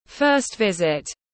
Xông đất tiếng anh gọi là first visit, phiên âm tiếng anh đọc là /ˈfɜːst ˈvɪz.ɪt/
First visit /ˈfɜːst ˈvɪz.ɪt/